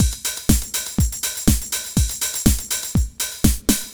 Beat 07 Full (122BPM).wav